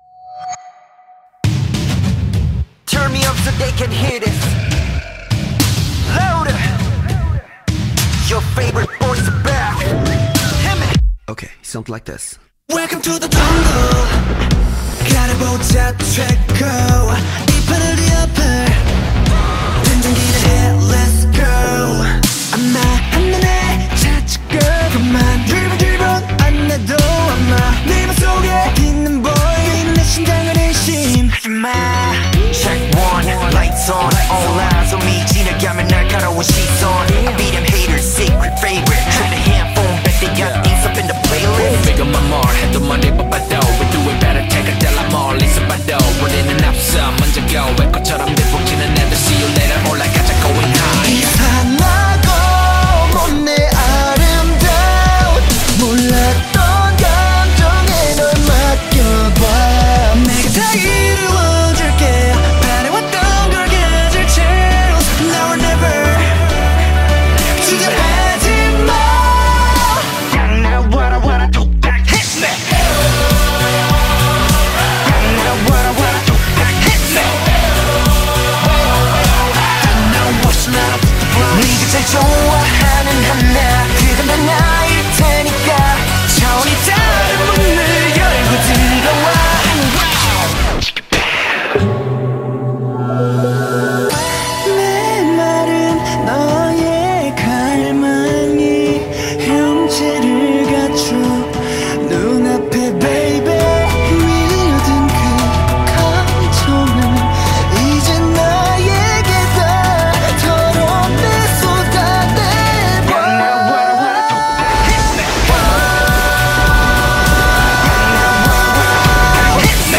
BPM101
Audio QualityPerfect (High Quality)